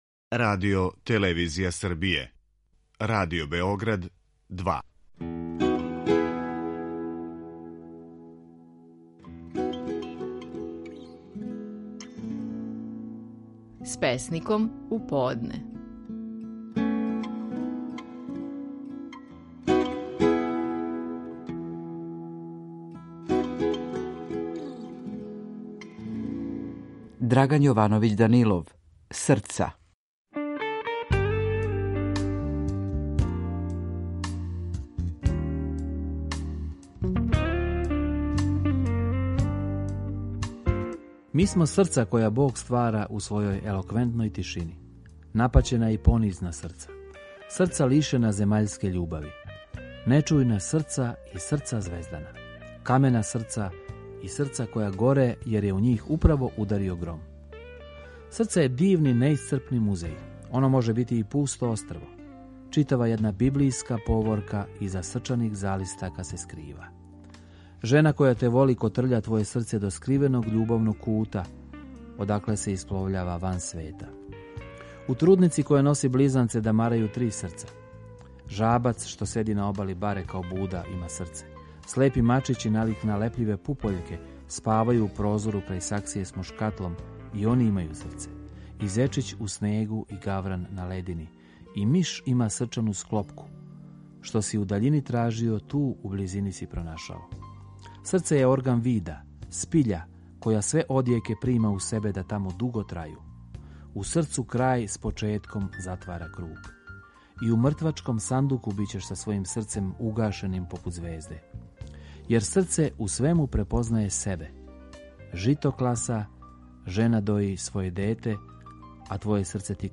Стихови наших најпознатијих песника, у интерпретацији аутора.
Своју песму „Затвореник у ружи", говори Драган Колунџија.